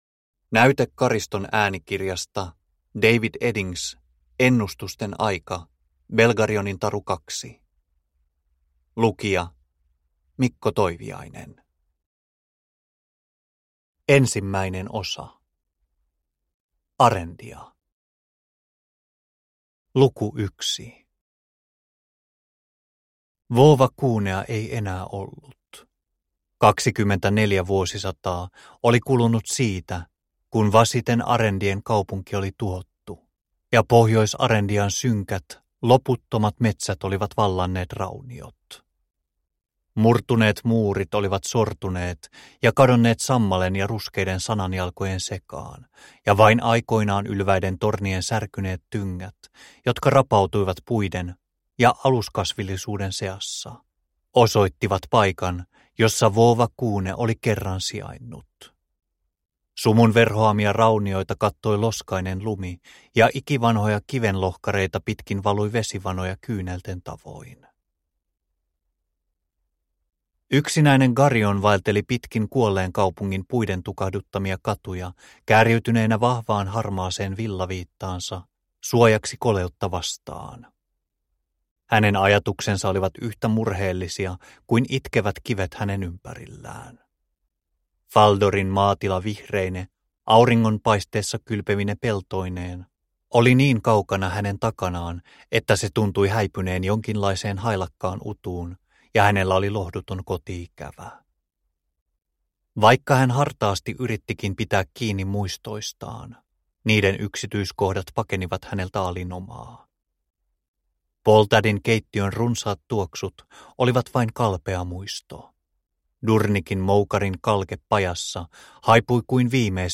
Produkttyp: Digitala böcker